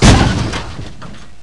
damage25_1.ogg